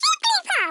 File:Jigglypuff voice sample EN.oga
Jigglypuff_voice_sample_EN.oga.mp3